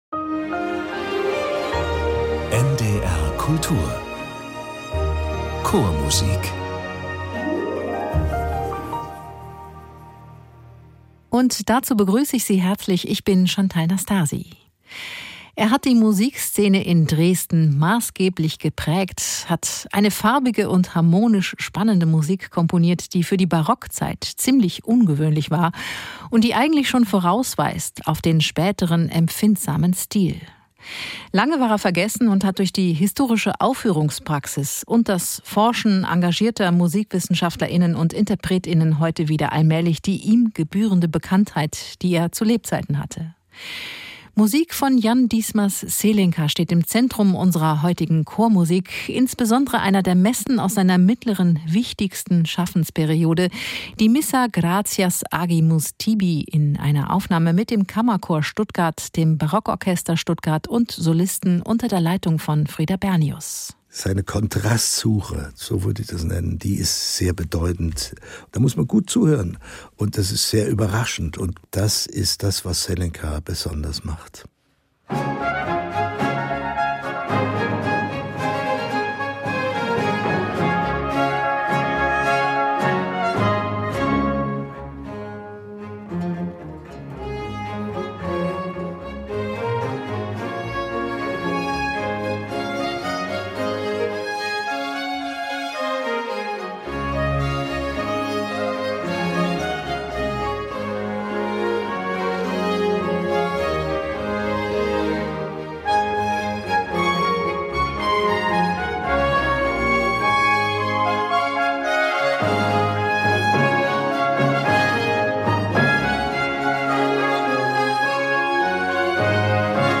Seine Harmonik und ungewöhnlich viel Rhythmik im Basso Continuo machen Zelenkas Vokalwerke spannend und zukunftsweisend.